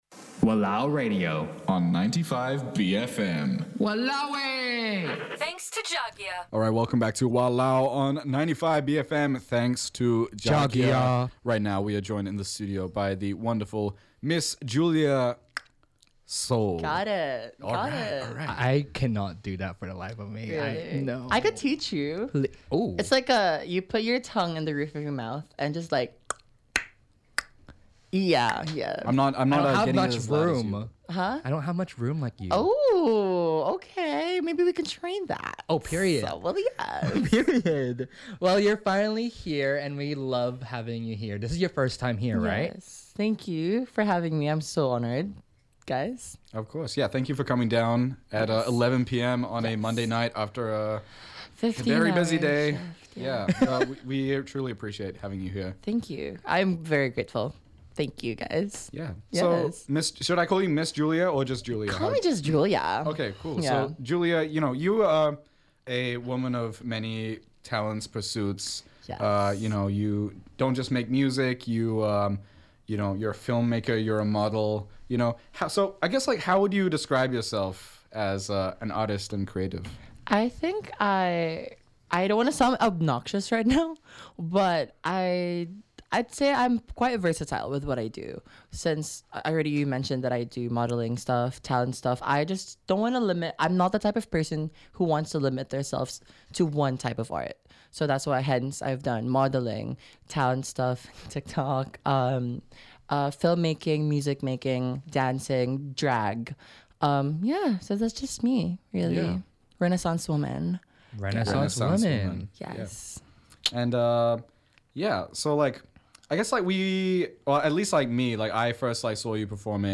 WALAO! Interview